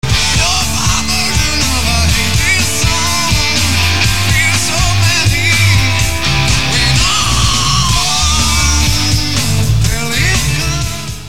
11/07/95 - Sports Arena: San Diego, CA [131m]